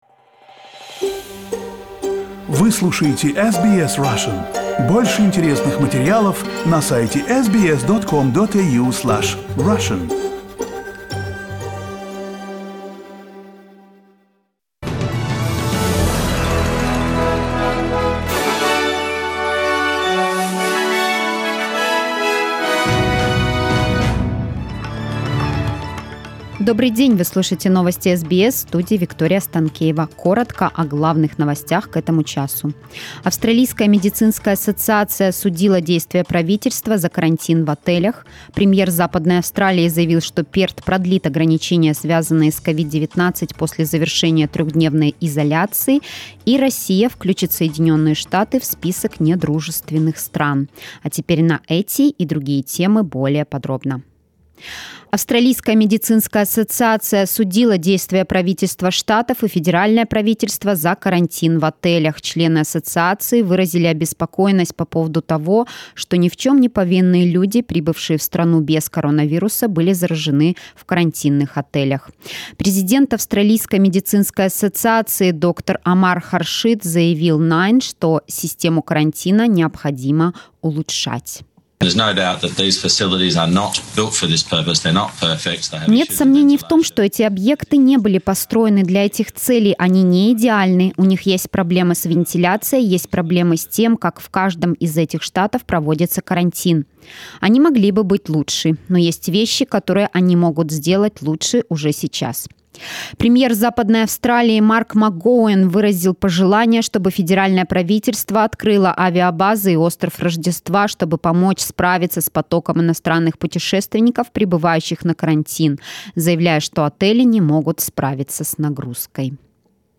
SBS news in Russian - 26.04